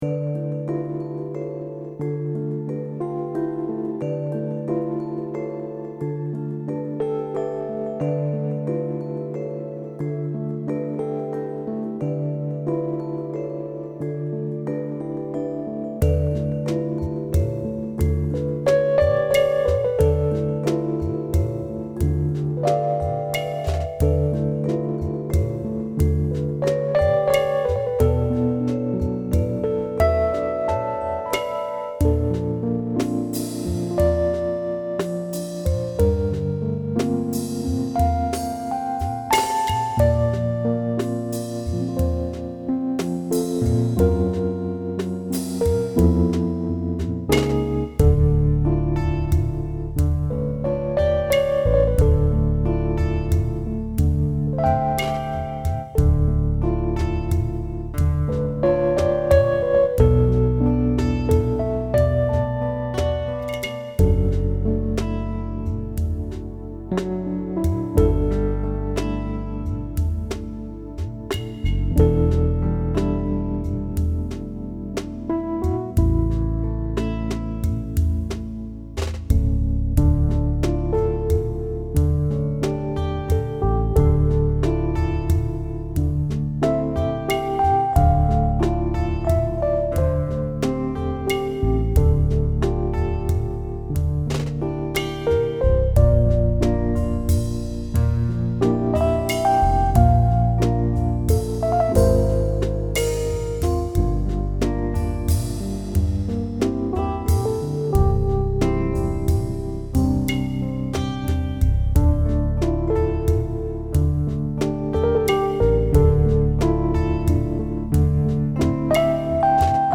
Download - Ambient / Magical Jazz